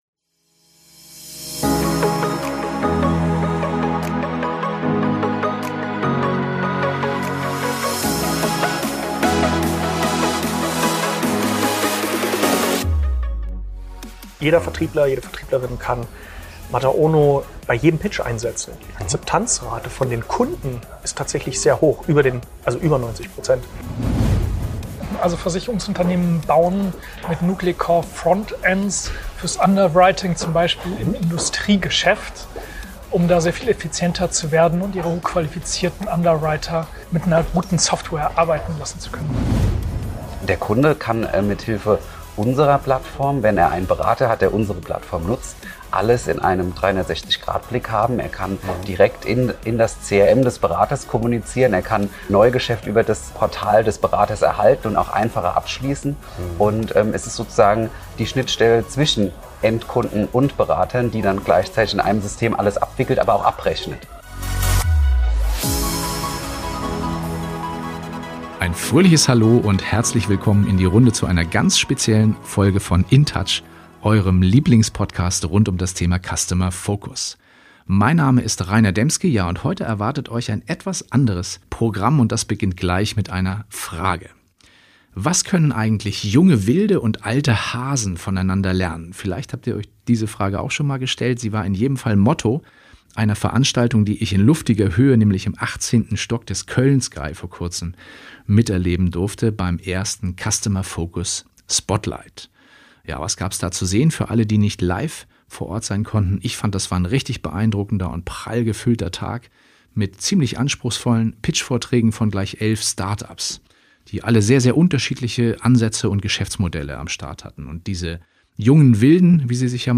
Beschreibung vor 3 Jahren "Start-up meets Grown-up": So lautete das Motto des ersten CUSTOMER FOCUS SPOTLIGHT am 27. September 2022. In luftiger Höher von18 Stockwerken über dem Rhein trafen sich in Köln elf Start-ups und Insurtechs mit großen Unternehmen der Versicherungswirtschaft zu einem intensiven Austausch auf Augenhöhe. Diese Chance haben wir uns natürlich nicht entgehen lassen und waren samt Mikrofon live vor Ort am Start.